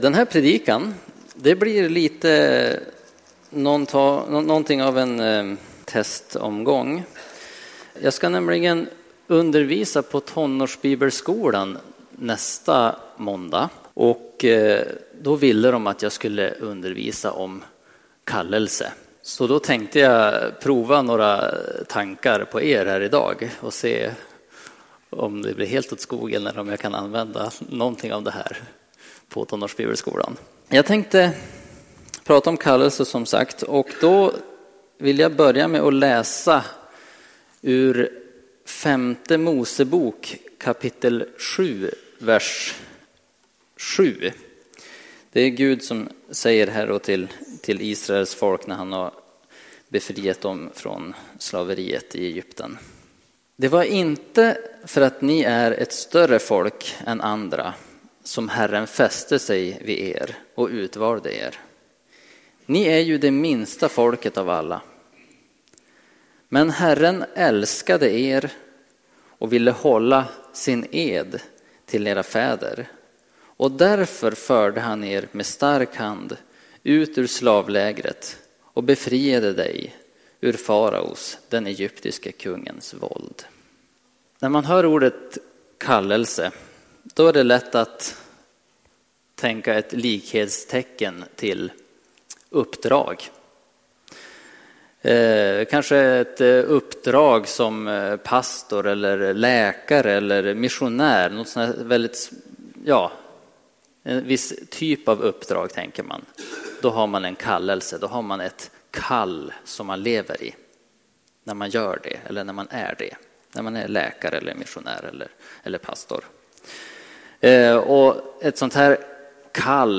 Predikningar